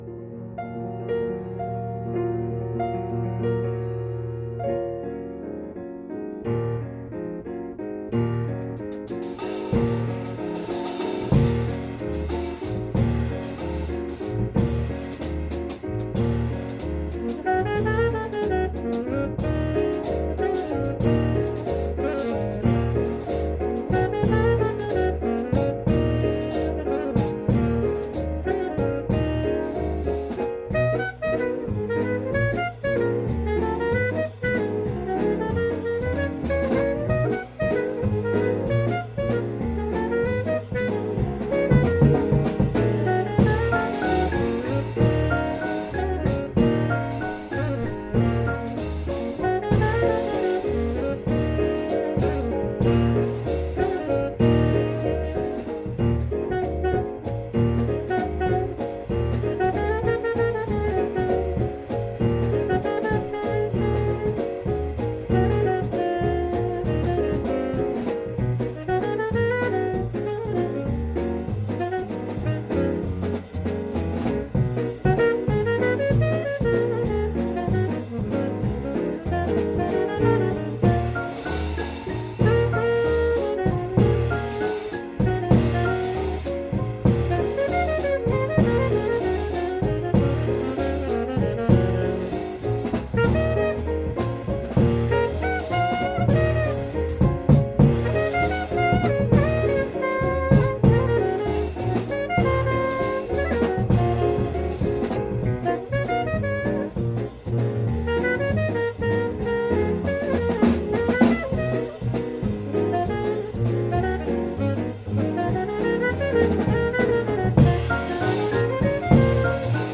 en concert
saxophone alto
piano
contrebasse
batterie.